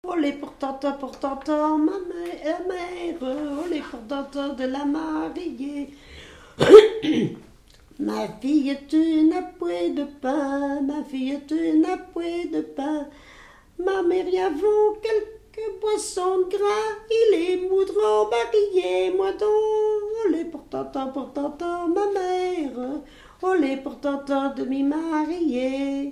Dialogue mère-fille
Pièce musicale inédite